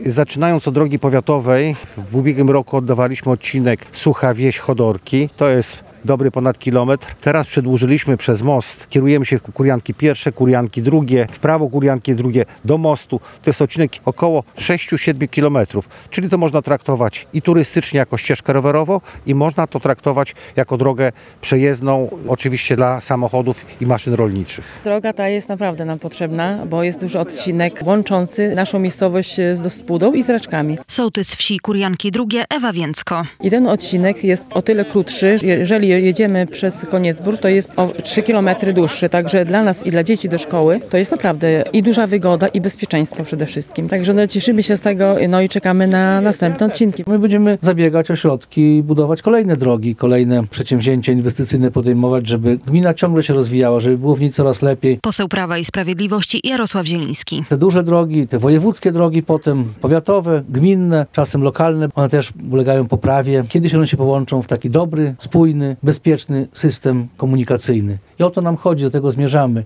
Kolejny etap modernizacji sieci dróg w gminie Raczki - relacja